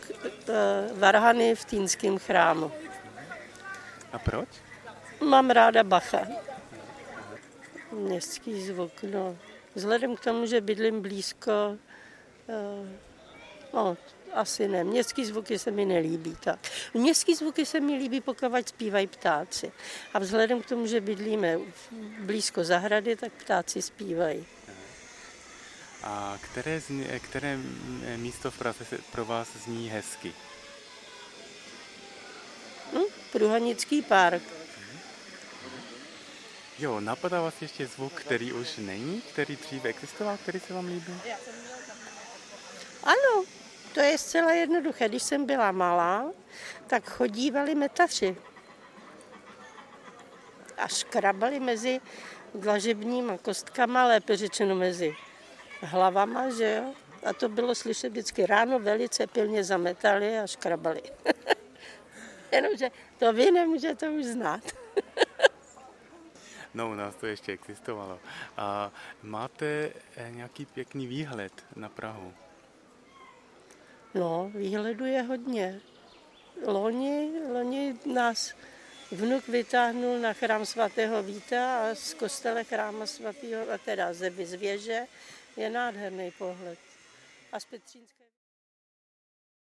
street sweepers | Zvuky Prahy / Sounds of Prague
Metaři
< Hoofs Family Frost > play pause stop mute unmute Metaři Tagy: rozhovory Autor: anon.